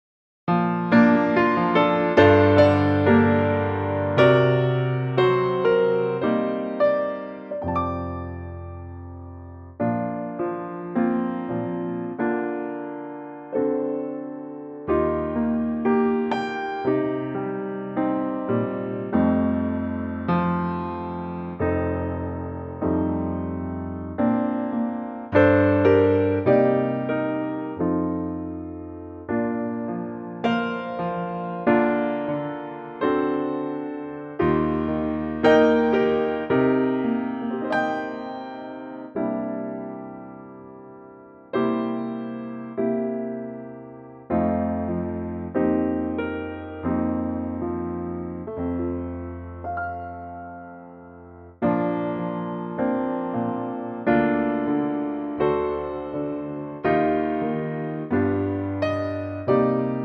piano intro and vocal in at 8 seconds
key - Bb - vocal range - Bb to D
Lovely piano only arrangement